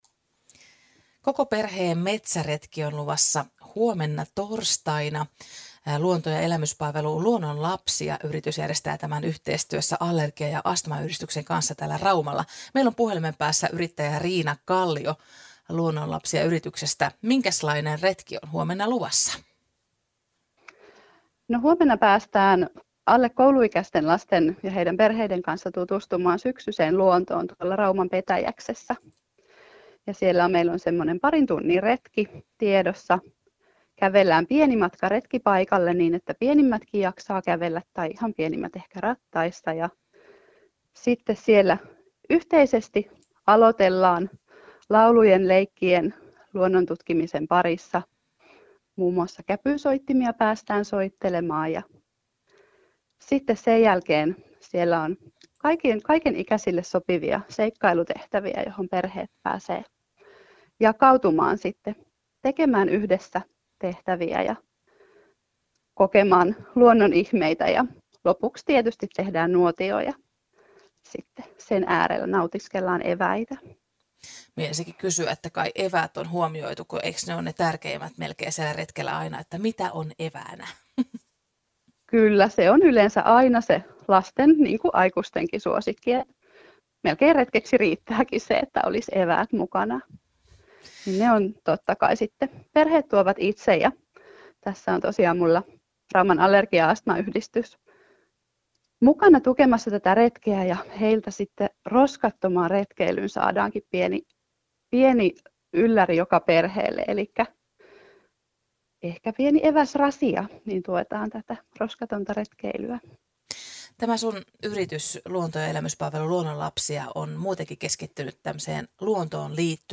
Ohjatut metsäretket tarjoavat matalan kynnyksen luonnontutkimiseen. Haastattelussa